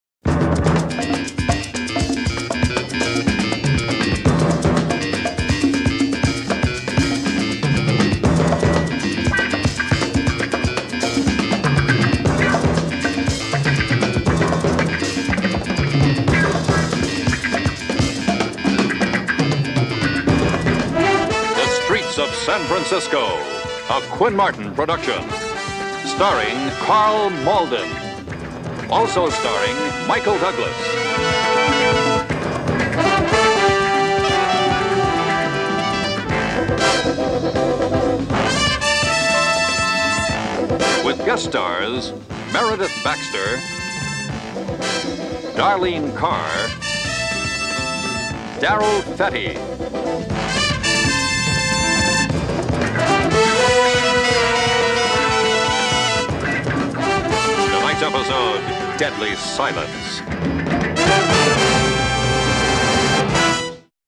SEASON 4 THEME MUSIC:
Opening Credits •